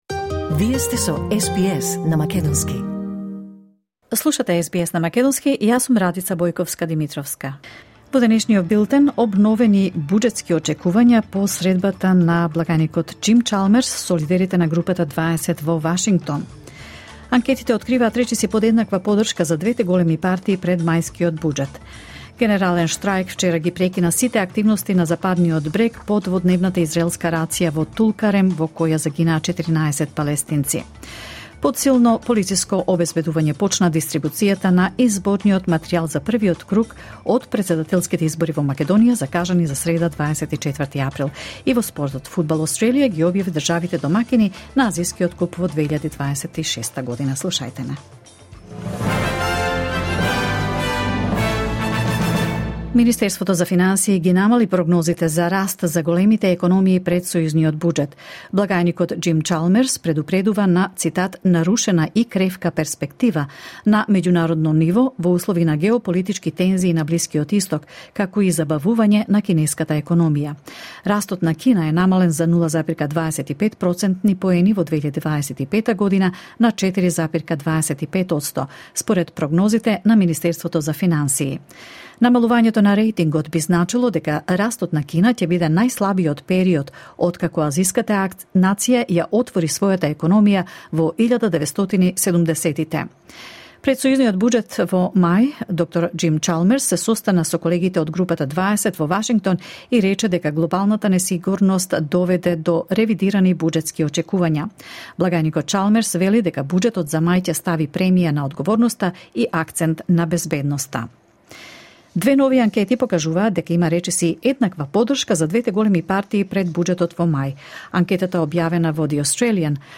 SBS News in Macedonian 22 April 2024